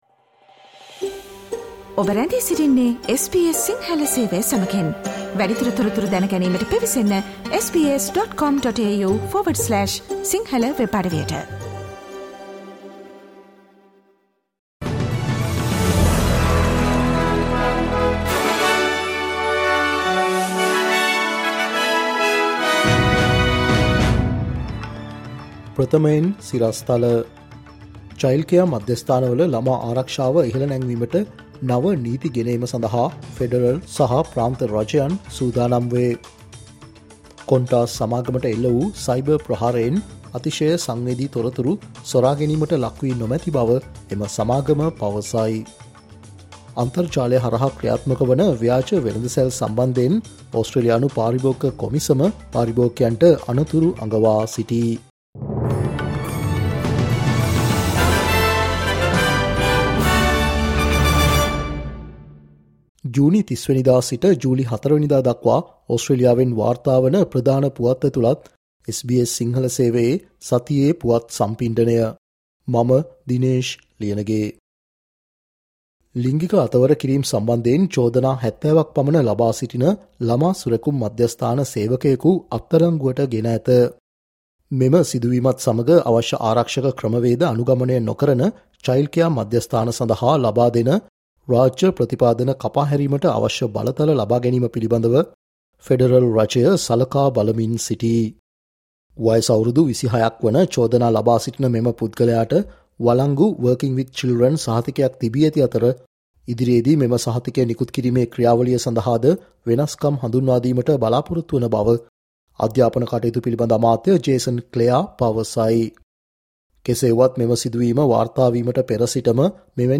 'මේ සතියේ ඔස්ට්‍රේලියාව': SBS සිංහල ගෙන එන සතියේ ඕස්ට්‍රේලියානු පුවත් සම්පිණ්ඩනය, ජුනි 30 - ජූලි 04